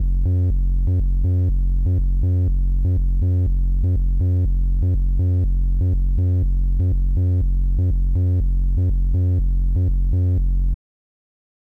I wired it up to a jack and scoped it and it showed a consistent signal matching what the filter was doing outputting around 2v again and upon sending it through my mixer, Korg really nailed the labeling because yep, it’s some post filter goodness but I have not yet had the aha moment with regards to what to do with this in a larger patch scale.
VCF-Out.wav